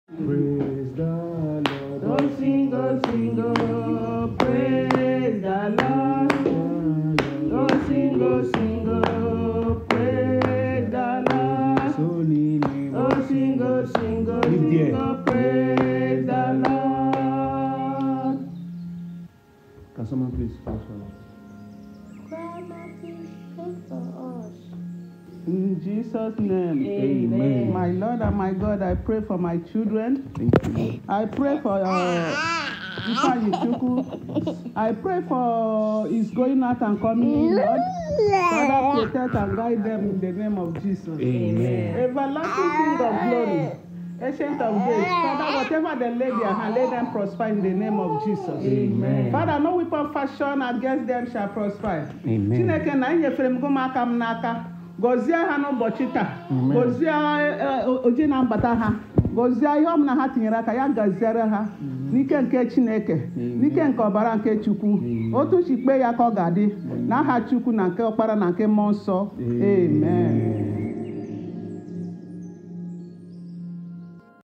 This powerful and emotionally resonant record